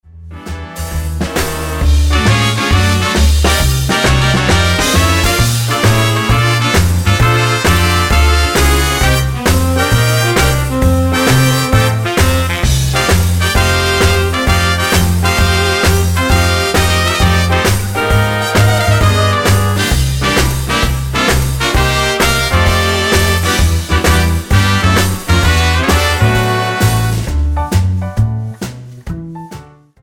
Tonart:D ohne Chor